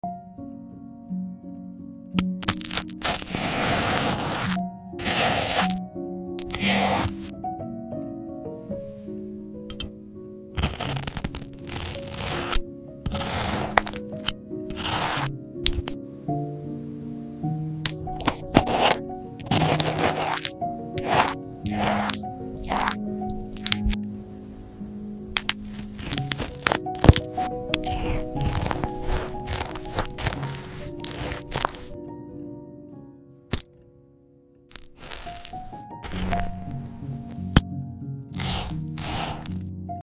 Your ASMR Toast Spread By Sound Effects Free Download